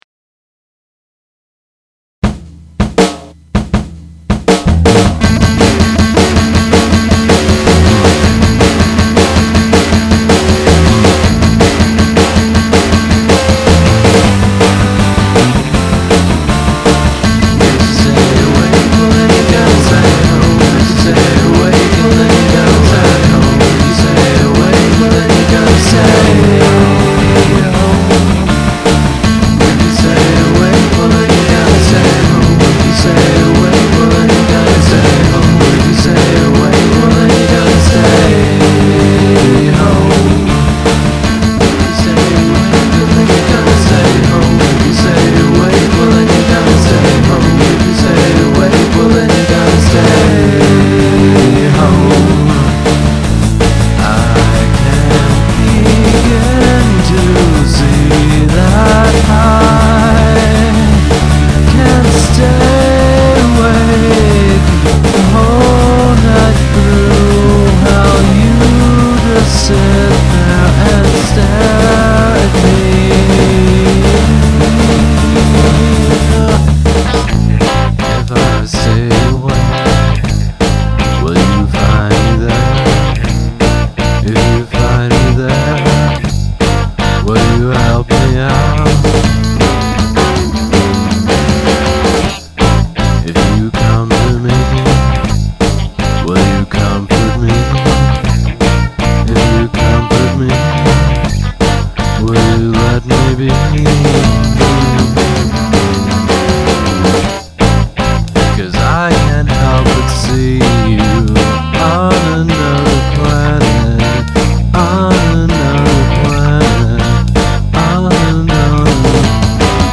As always, I think the bass work here is super.